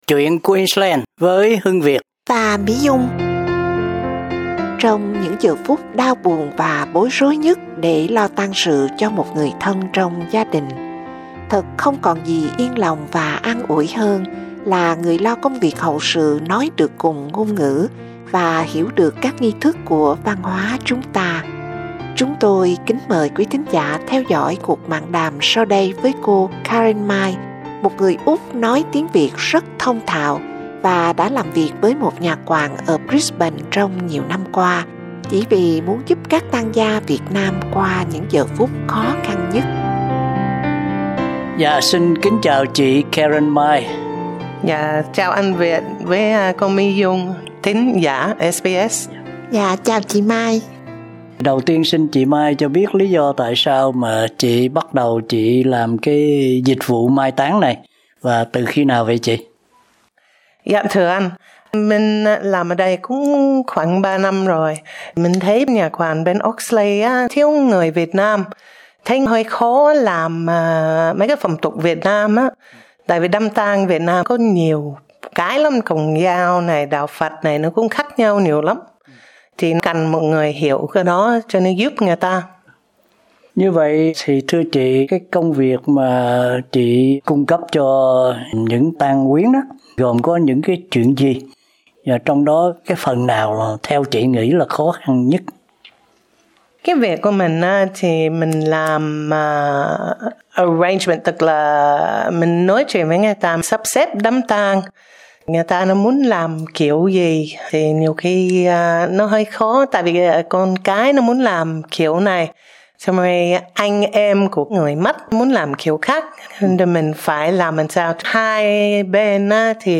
cuộc mạn đàm